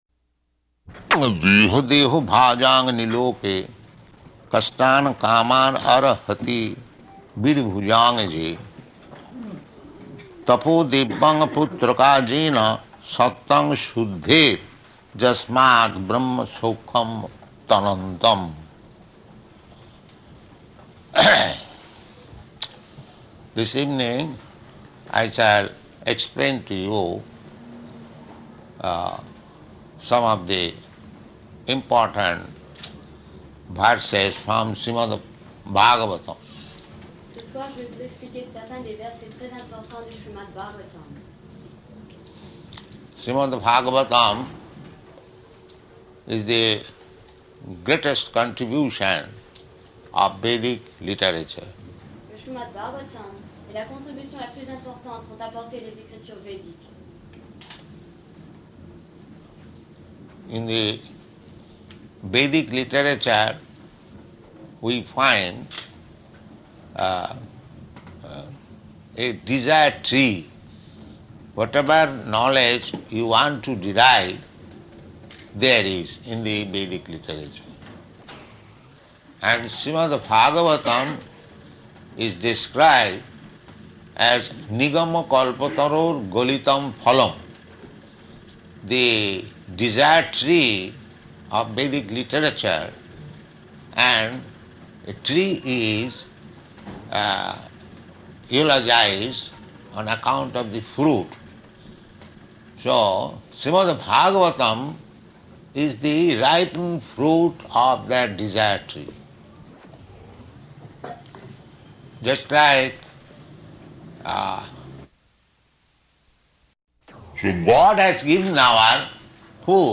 Location: Paris